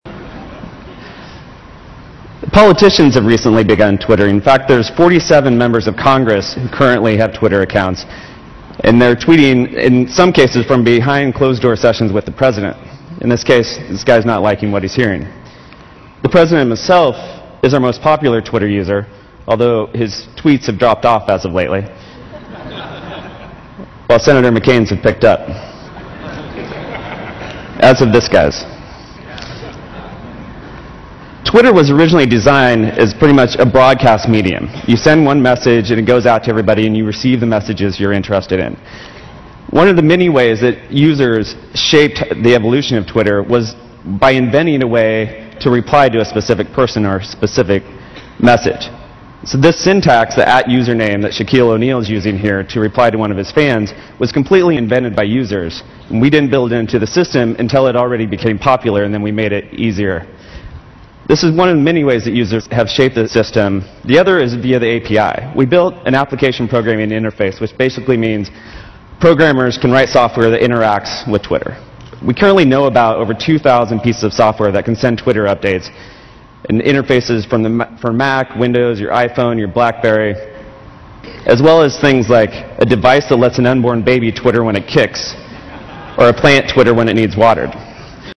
财富精英励志演讲68：分享生活点滴(3) 听力文件下载—在线英语听力室